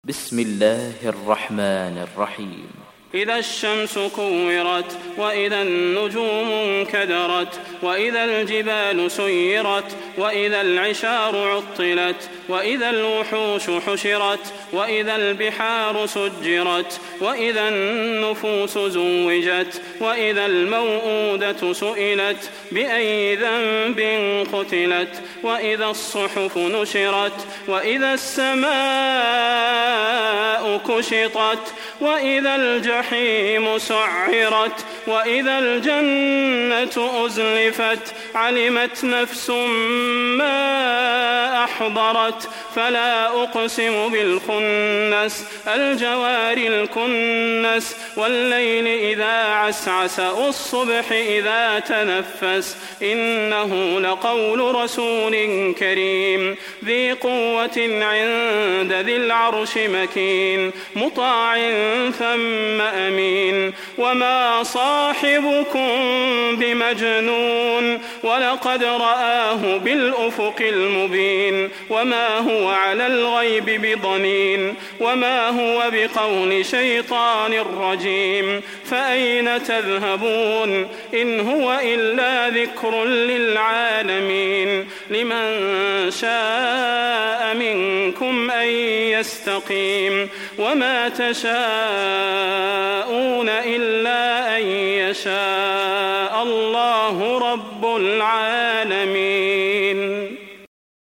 تحميل سورة التكوير mp3 بصوت صلاح البدير برواية حفص عن عاصم, تحميل استماع القرآن الكريم على الجوال mp3 كاملا بروابط مباشرة وسريعة